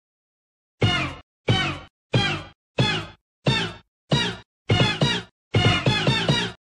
jet set radio 1 2 3 4 Meme Sound Effect